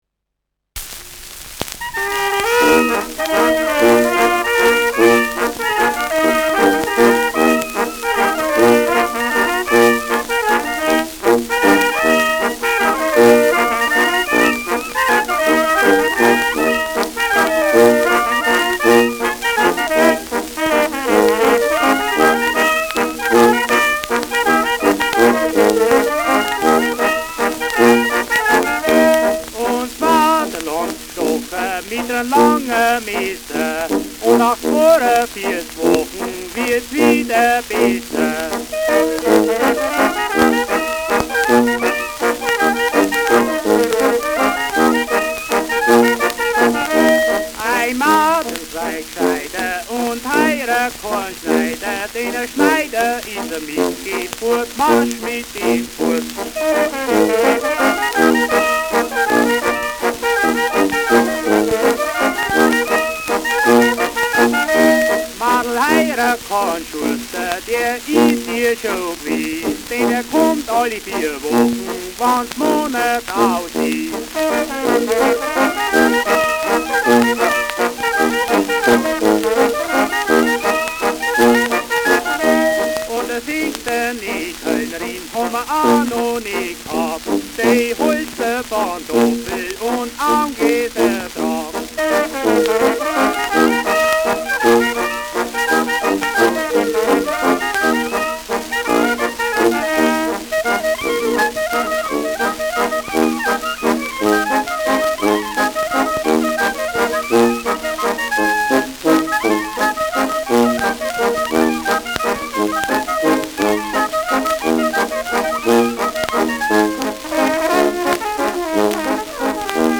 Schellackplatte
präsentes Rauschen : leichtes Knistern : vereinzeltes Knacken
Kapelle Die Alten, Alfeld (Interpretation)
[Nürnberg] (Aufnahmeort)